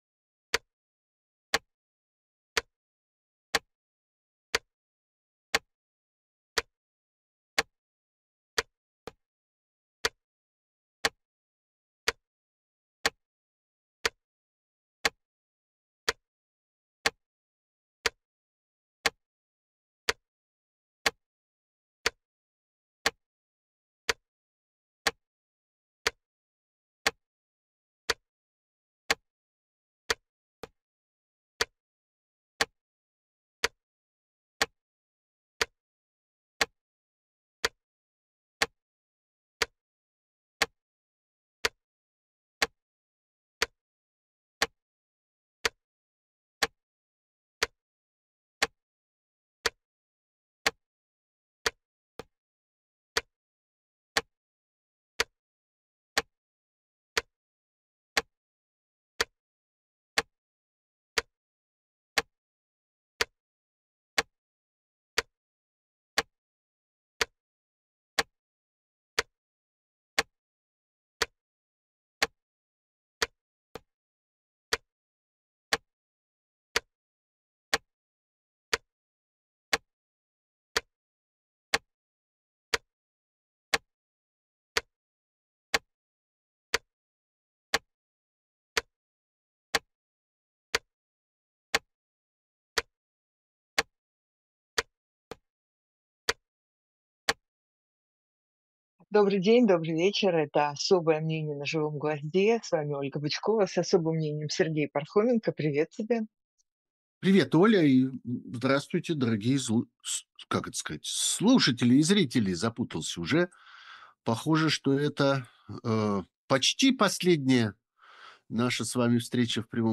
Эфир ведёт Ольга Бычкова